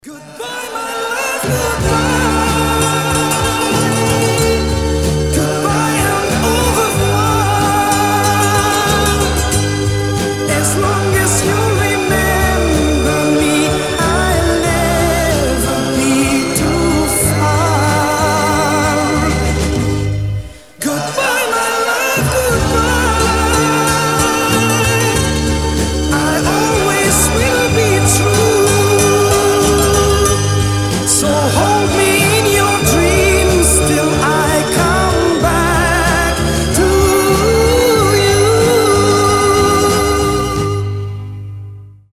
• Качество: 320, Stereo
70-е